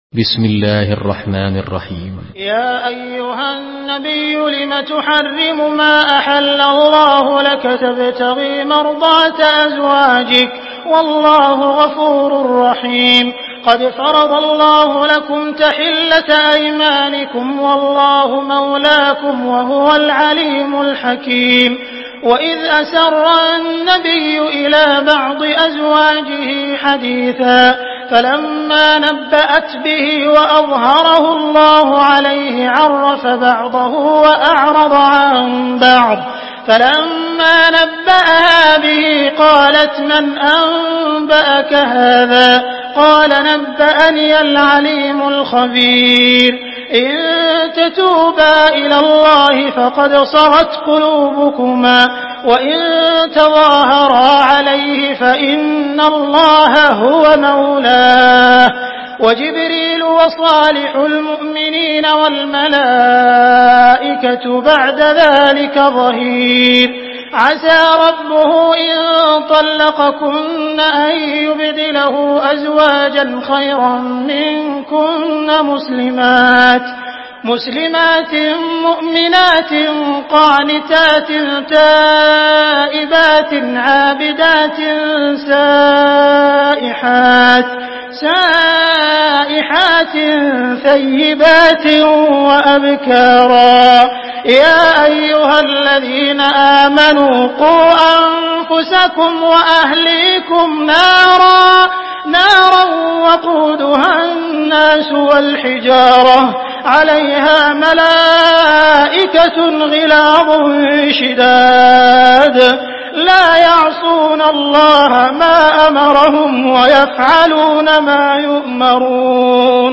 Surah At-Tahrim MP3 in the Voice of Abdul Rahman Al Sudais in Hafs Narration
Murattal